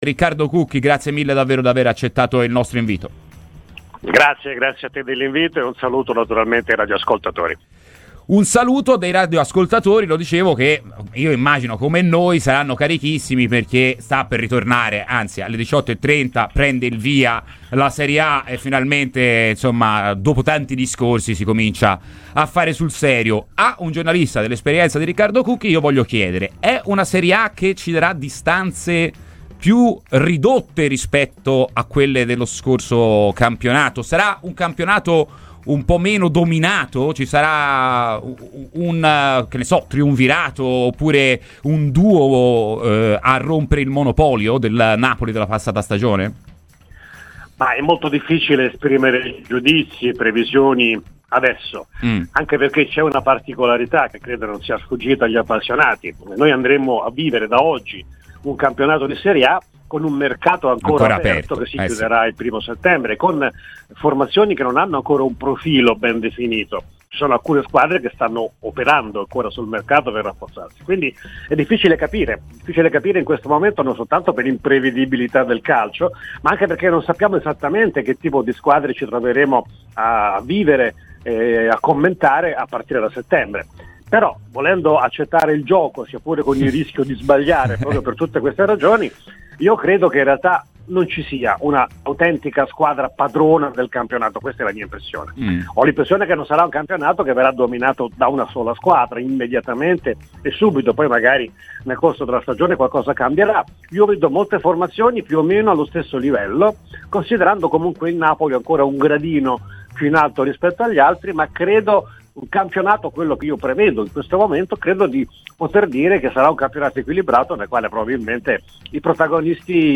Riccardo Cucchi, giornalista ed ex radiocronista sportivo, ha parlato a Radio FirenzeViola durante la trasmissione Firenze in Campo:.